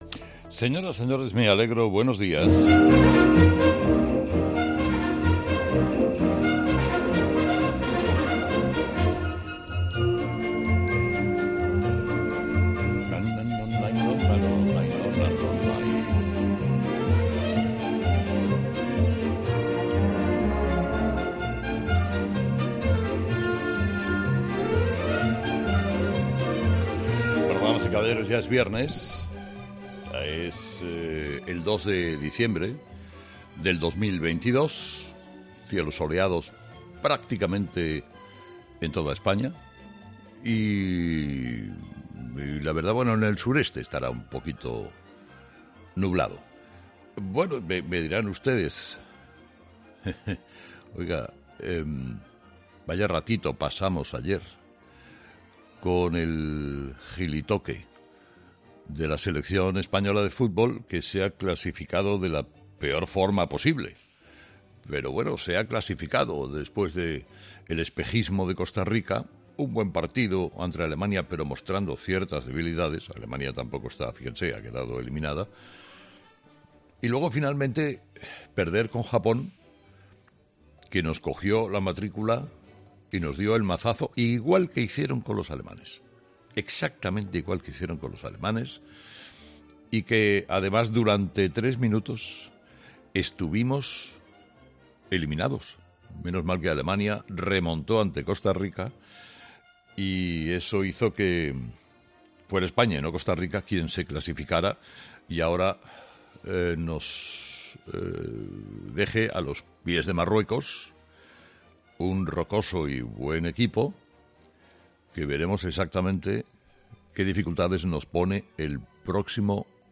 Carlos Herrera, director y presentador de 'Herrera en COPE', ha comenzado el programa de este viernes analizando las principales claves de la jornada, que pasan, entre otros asuntos, por la derrota de España ante Japón en el Mundial de Qatar 2022 que, aun así, le permitió pasar a los octavos de final.